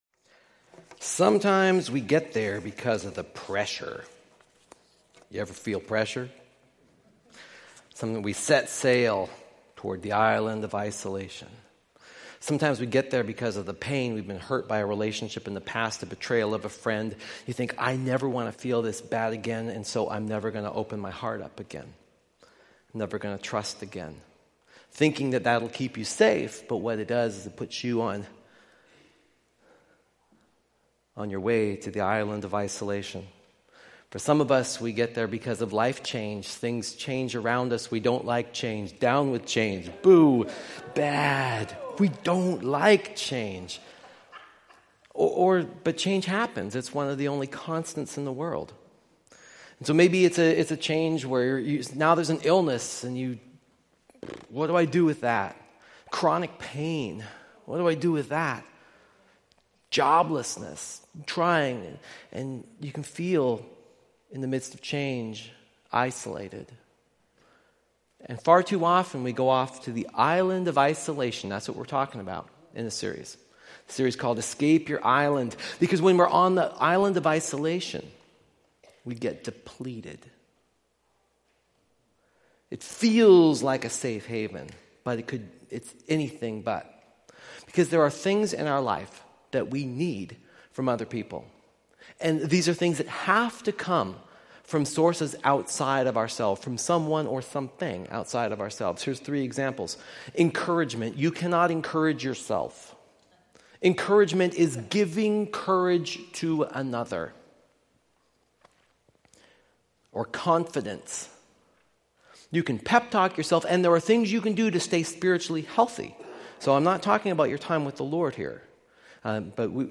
Sermons from Columbia Grove Covenant Church in East Wenatchee WA.